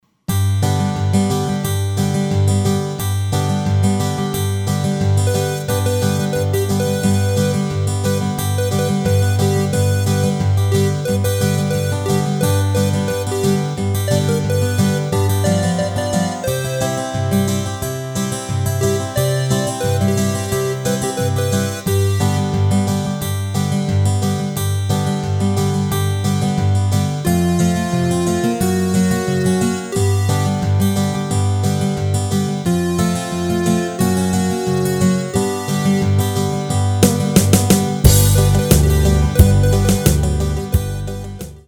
Rubrika: Folk, Country
Karaoke
HUDEBNÍ PODKLADY V AUDIO A VIDEO SOUBORECH